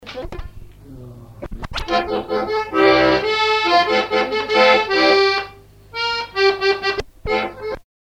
Base d'archives ethnographiques
Fonction d'après l'analyste gestuel : à marcher ;
Genre laisse
Catégorie Pièce musicale inédite